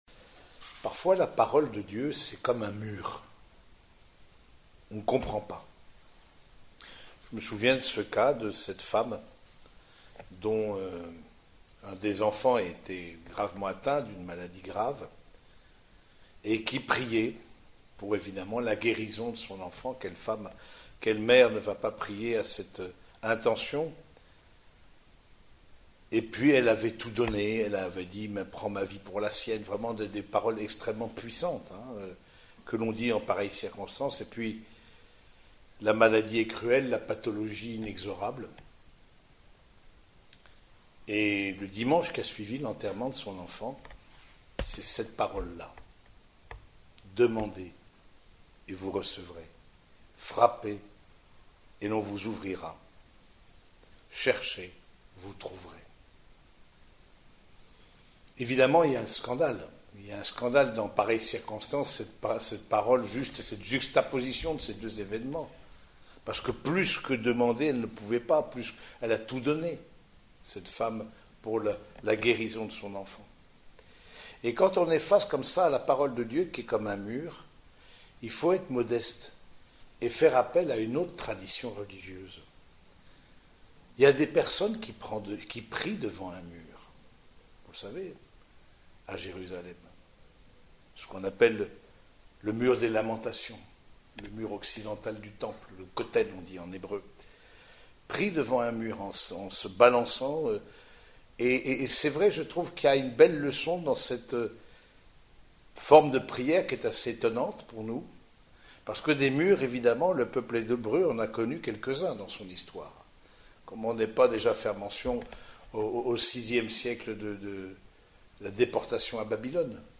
Homélie du 17e dimanche du Temps Ordinaire
Cette homélie a été prononcée au cours de la messe dominicale célébrée dans la chapelle des sœurs franciscaines de Compiègne.